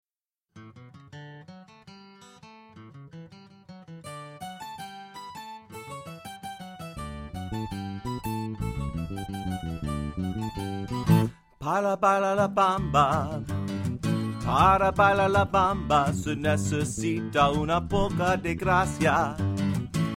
Traditional Spanish Song Lyrics and Sound Clip